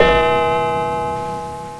snd_7912_Bell.wav